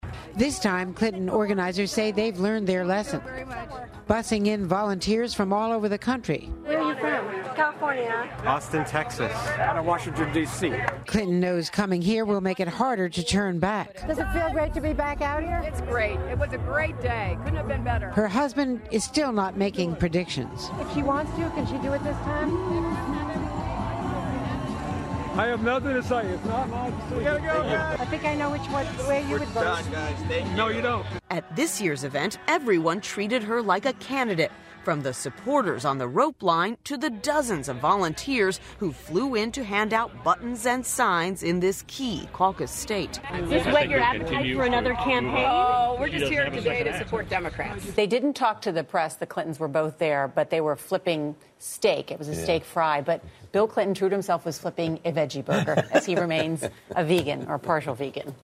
A clip played of Mitchell shouting a question to the former secretary of state: "Does it feel great to be back out here?"